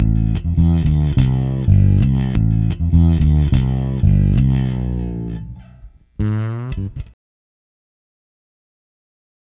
Ala Brzl 1 Fnky Bass-A.wav